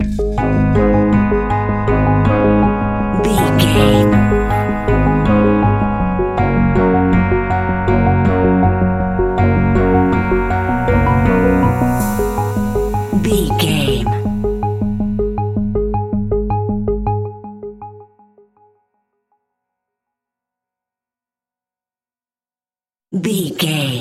Aeolian/Minor
G#
Slow
ominous
dark
haunting
eerie
futuristic
piano
synthesiser
drums
creepy
horror music